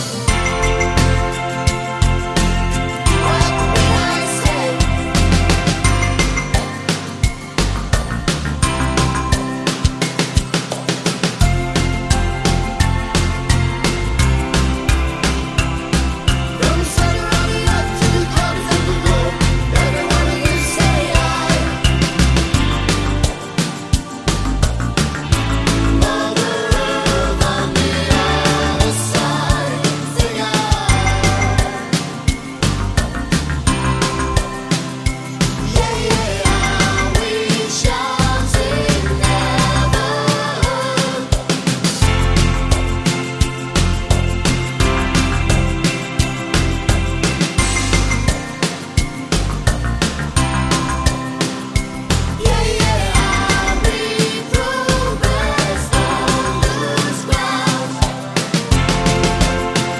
Minus Sax Solo Pop (1980s) 3:56 Buy £1.50